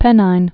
(pĕnīn)